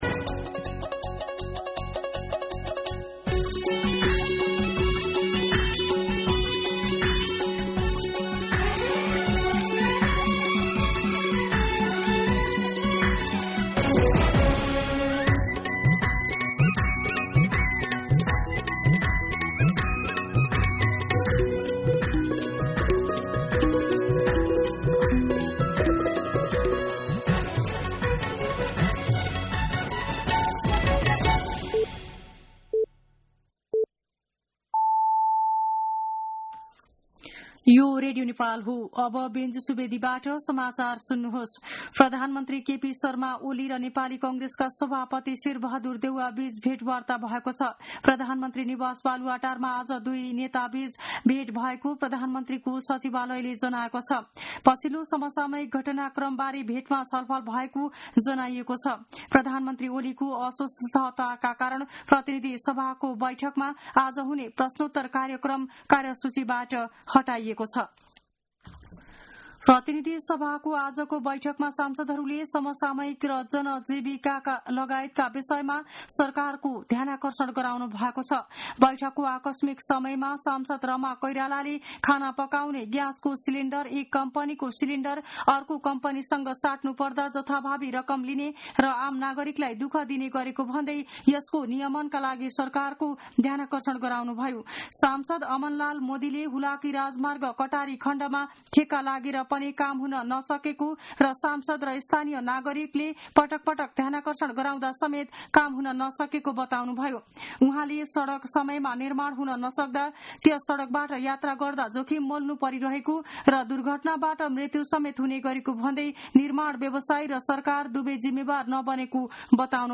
दिउँसो १ बजेको नेपाली समाचार : ६ जेठ , २०८२